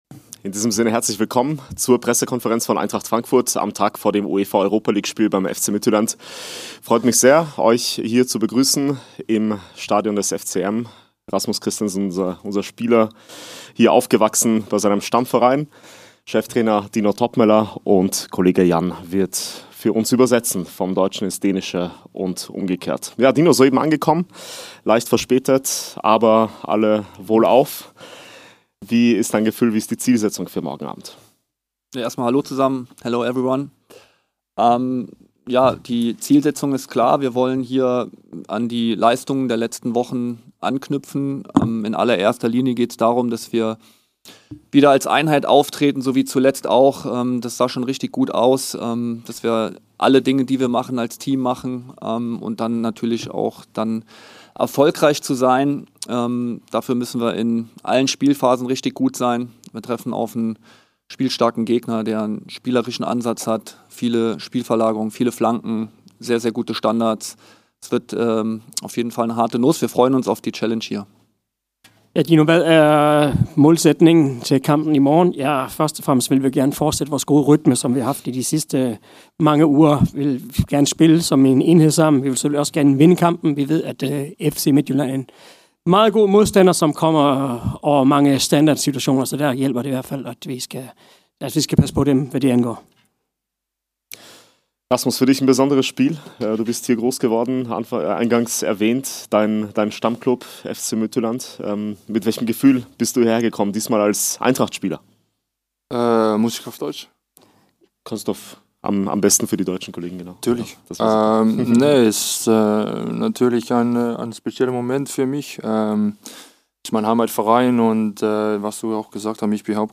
Die Pressekonferenz mit unserem Cheftrainer Dino Toppmöller und Rasmus Kristensen vor dem Europa-League-Spiel gegen den dänischen Meister.